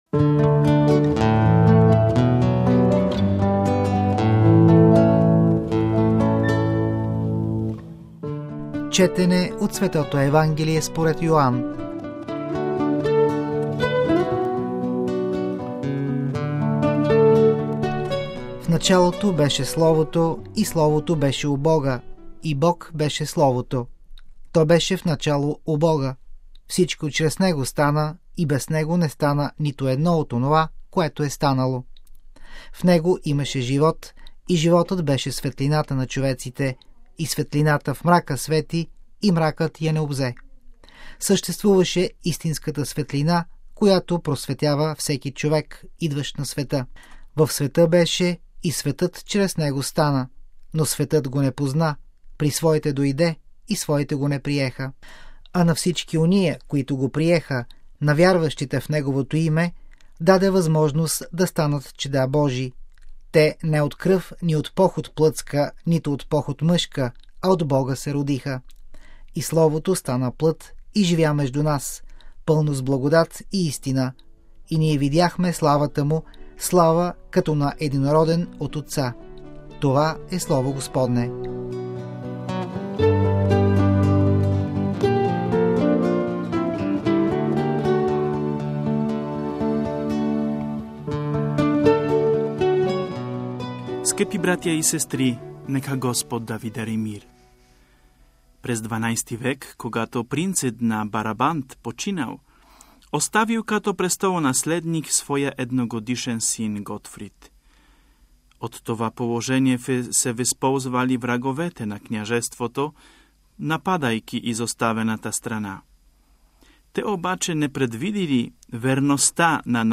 Рождество Христово 2005